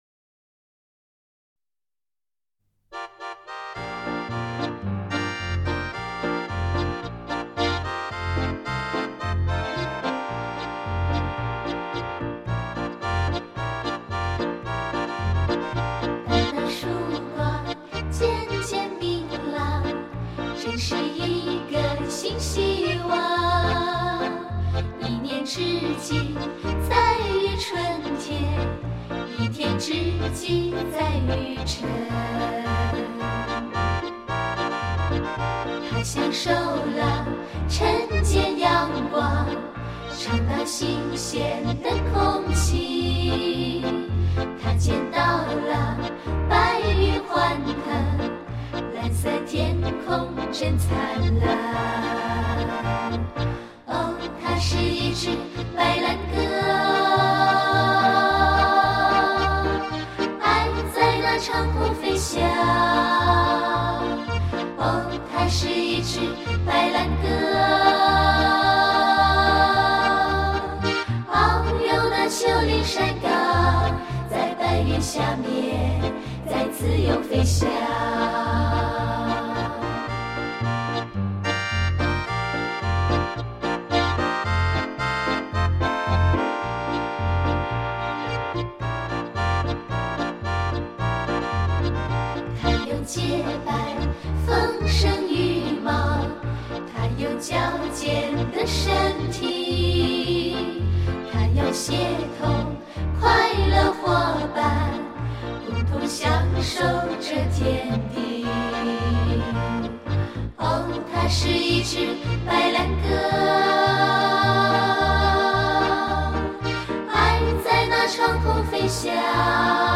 这是一张值得细意品尝的人声佳作
由于两个团队均用上通俗混合美声的唱法
态度严谨、充滿节奏感及生命力的歌声，同时洋溢着亲切、纯真、甜美与暖洋洋的气氛，令人听得相当惬意。